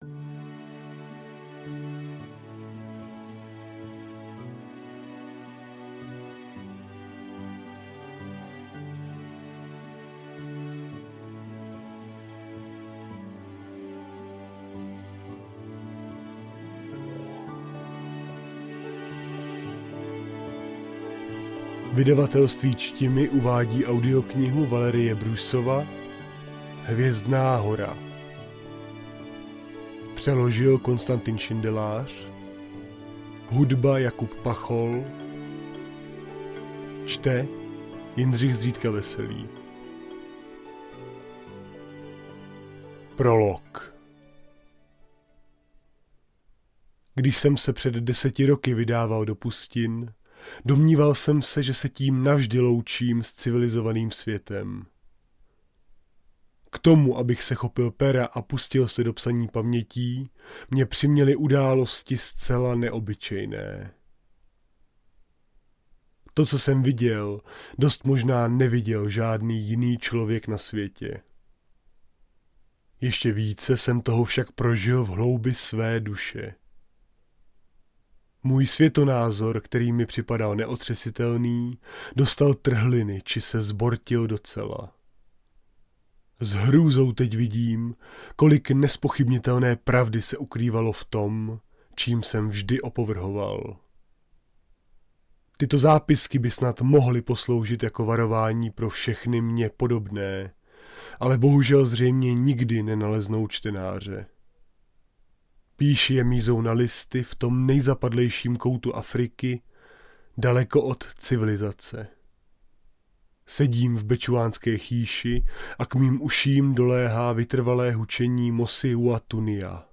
Kategorie: Sci-fi
01-Prolog-1.mp3